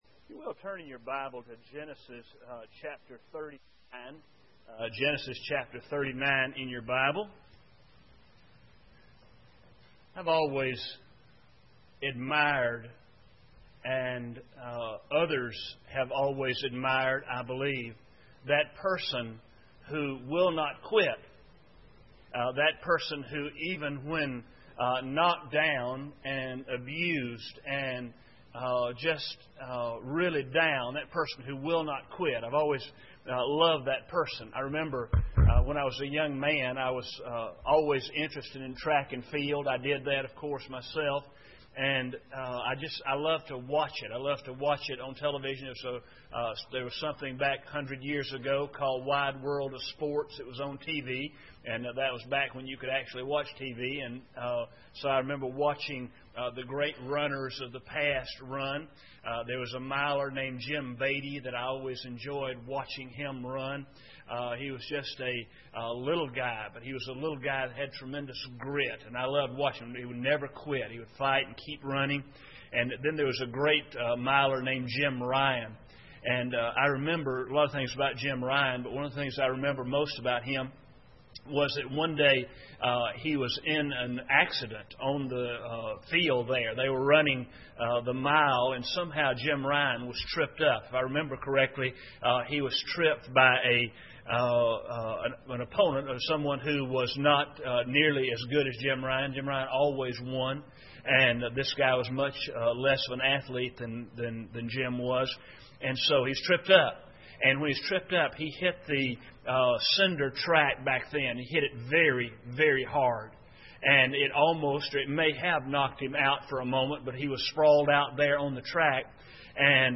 Genesis 39:1-23 Service Type: Sunday Evening Bible Text